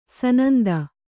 ♪ sananda